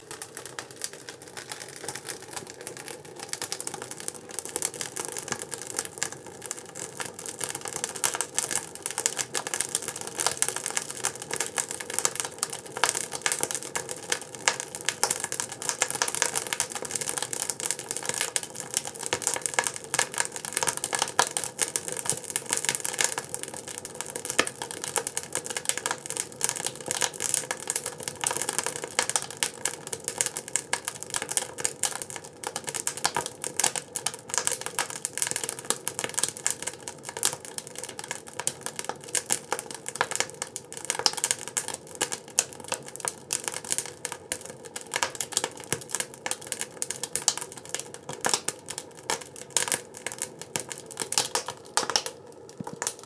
Leivinuuni lämpiää.
Share Facebook X Next Leivinuuni lämpiää mökillä, kuusipuu rätisee palaessaan.